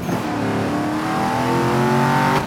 Index of /server/sound/vehicles/lwcars/eldorado